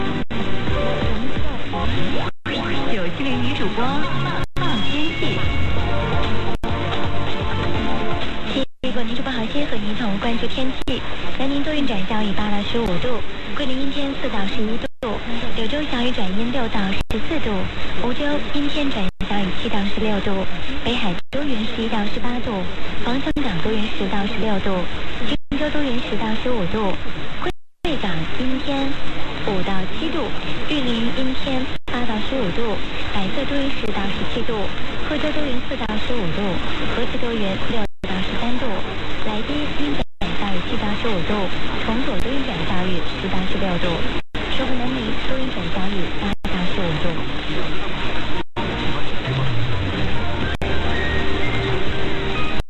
Tilalle tuli tämä 97.0 Hostess Radio eli kiinaksi 9-7-0 (jiu-qi-ling) Nǚ zhǔbō, joka nimensä mukaisesti käyttää vain naisjuontajia.